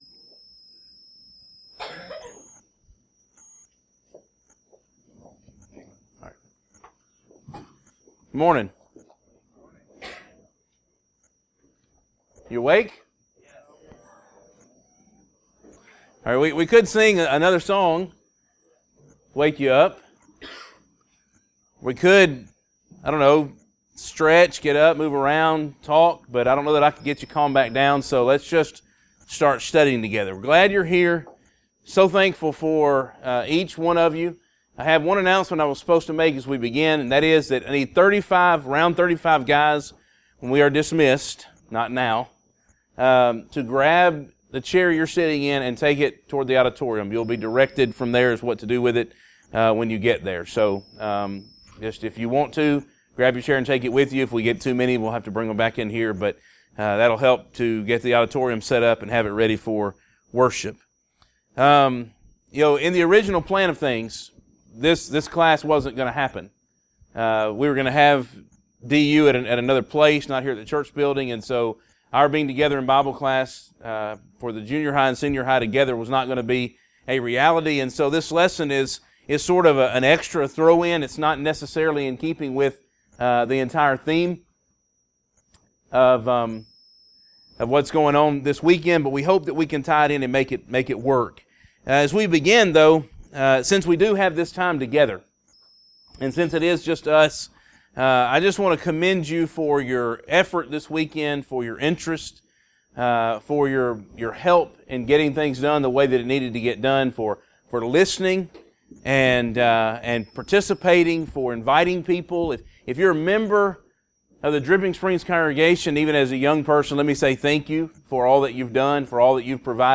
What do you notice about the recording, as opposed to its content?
Event: Discipleship U 2016 Theme/Title: Encountering Christ: Experience the Majesty of Jesus